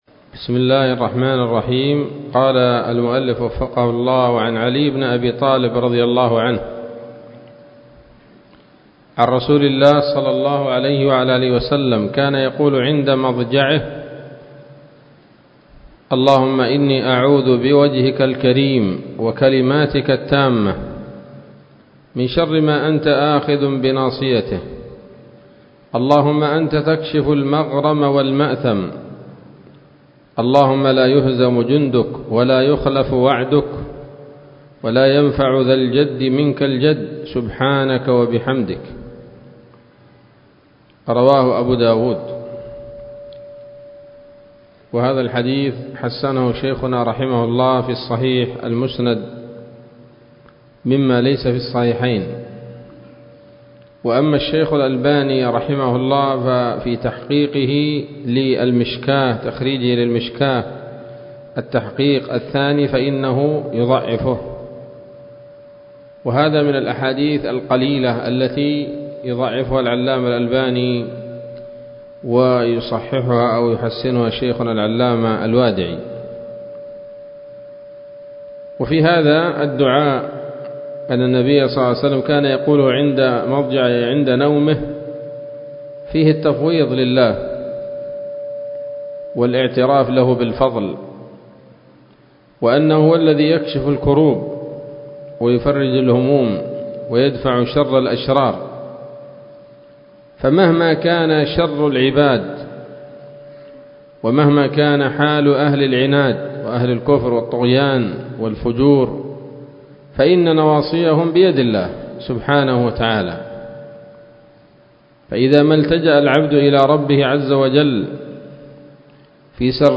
الدرس السابع عشر من رياض الأبرار من صحيح الأذكار